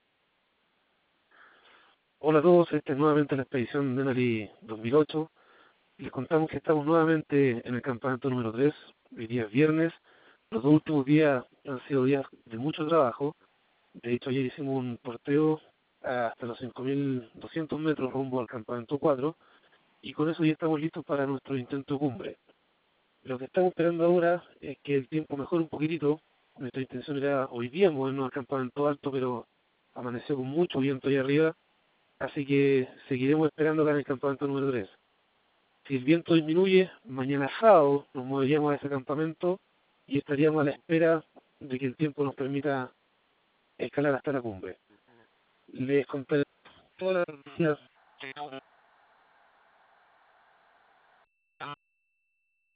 • Name: Campamento 3